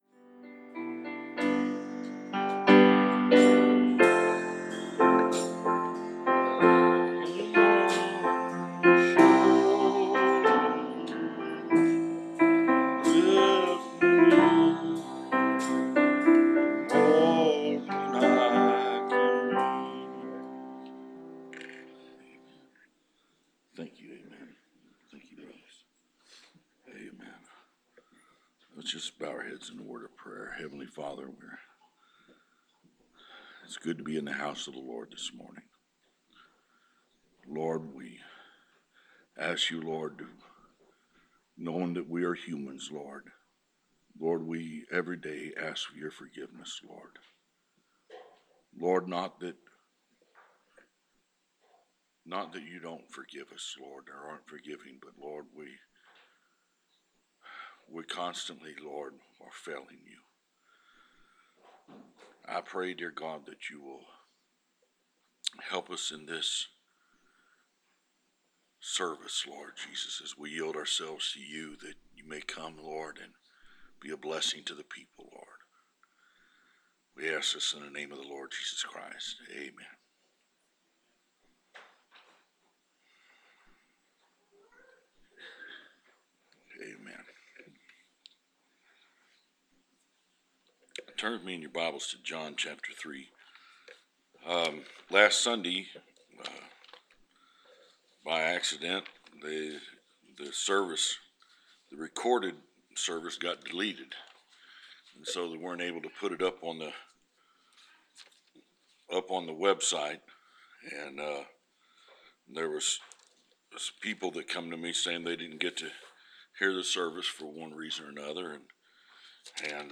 Preached June 12, 2016.